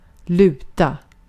Ääntäminen
IPA : /lɪst/